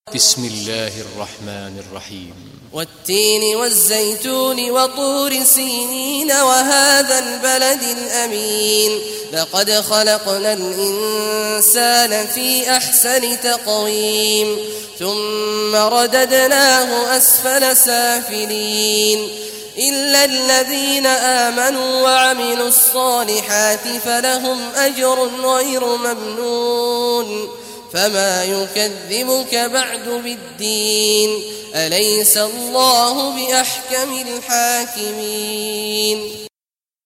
Surah Tin Recitation by Sheikh Awad Al Juhany
Surah Tin, listen or play online mp3 tilawat in Arabic in the beautiful voice of Sheikh Abdullah Awad Juhany.